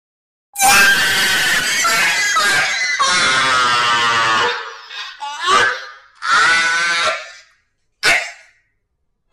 TIKTOK LAUGH
tiktok-laugh.mp3